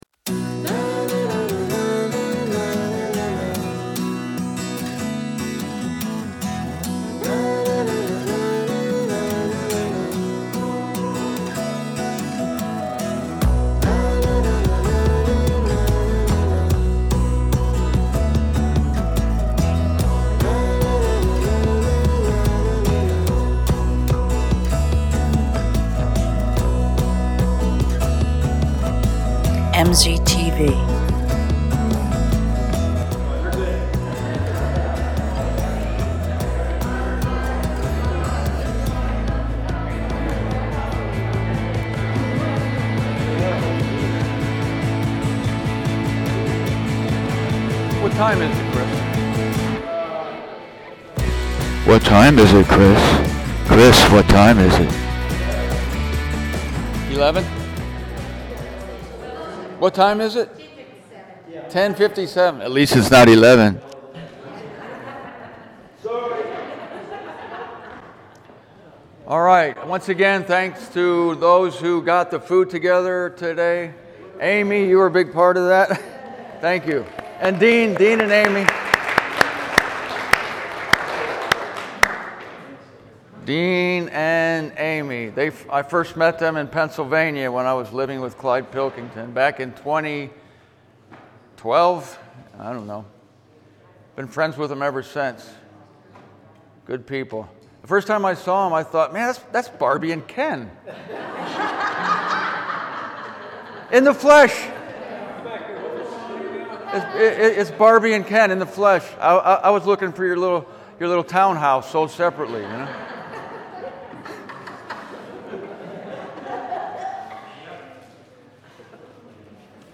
Here, at last, are the final talks of the final day of the Missouri Conference of 2020.